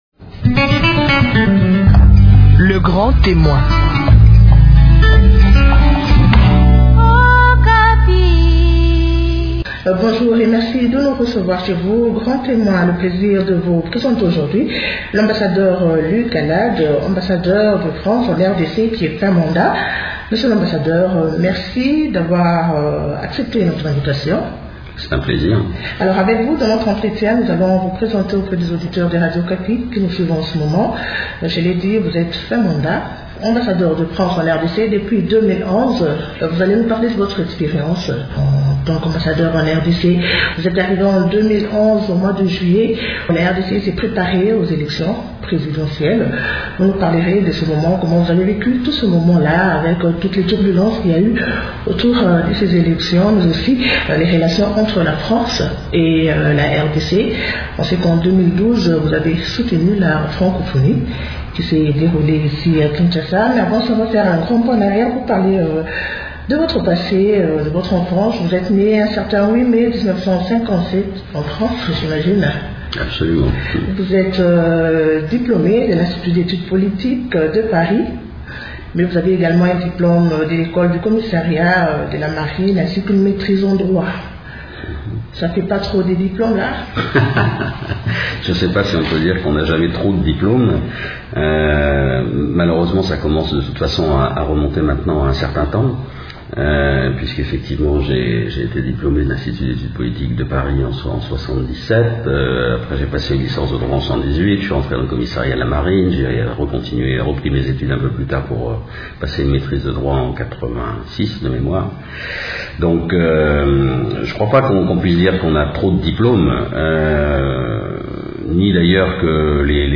Avant de quitter son poste de Kinshasa, il accordé un entretien à Grand Témoin.